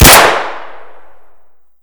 shoot_2.ogg